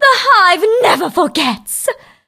bea_die_vo_03.ogg